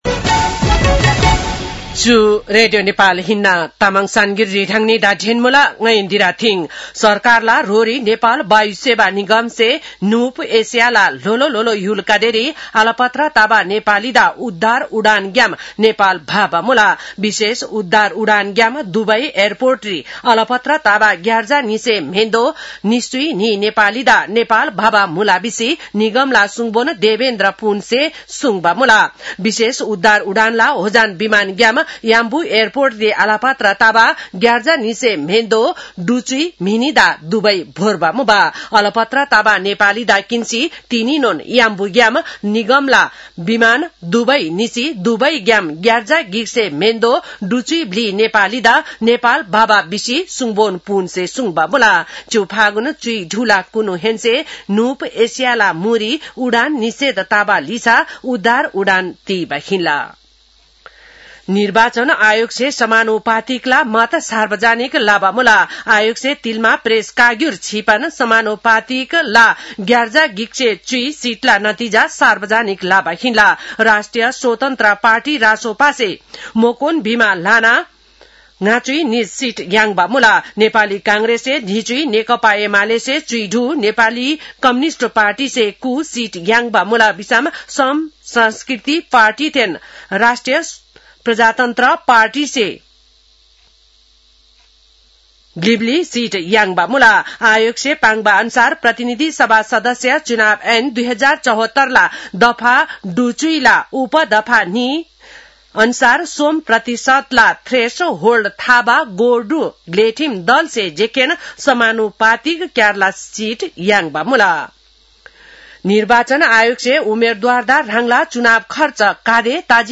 तामाङ भाषाको समाचार : २९ फागुन , २०८२
Tamang-news-11-29.mp3